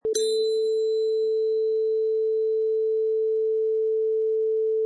Wav sample: Triangle Hit 2
Single ping of a percussion triangle
Product Info: 48k 24bit Stereo
Category: Percussion / Triangle
Try preview above (pink tone added for copyright).
Triangle_Hit_2.mp3